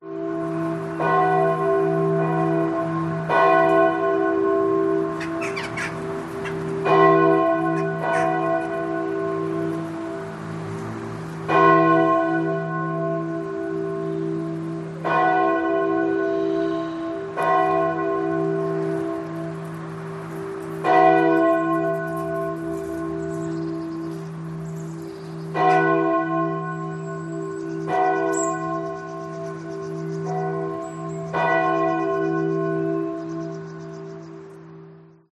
Звуки церковных колоколов
Звон колоколов близкой церкви, пение птиц и отдаленный шум улицы